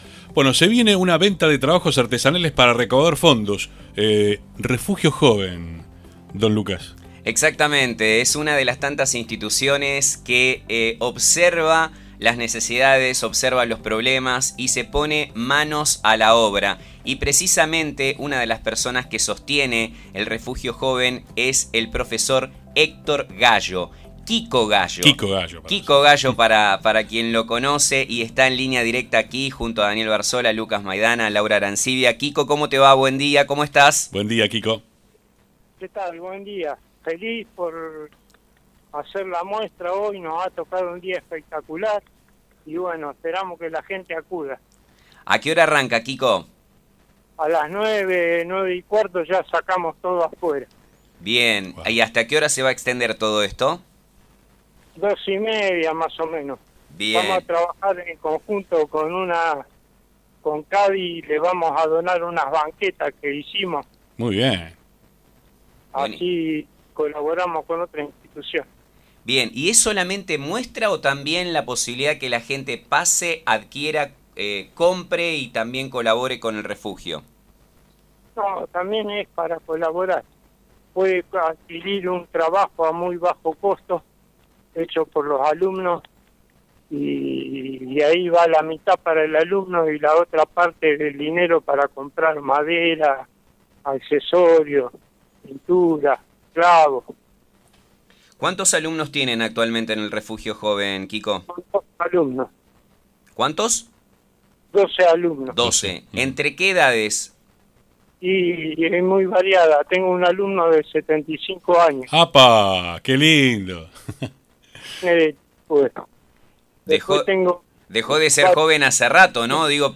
audio entrevista completa